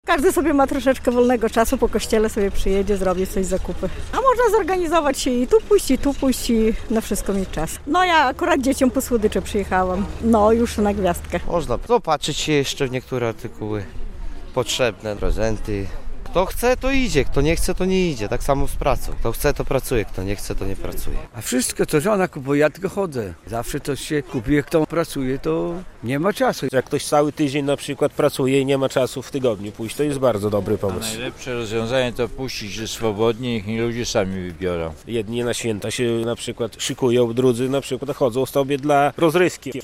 Białostoczanie o niedzieli handlowej